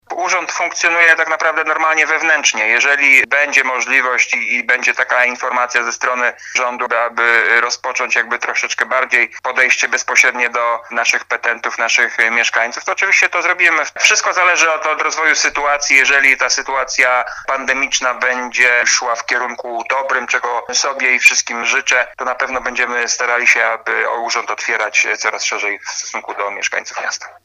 Jak wyjaśnia wiceprezydent miasta Dariusz Lesicki na razie nie dojdzie do poluzowania rygorów: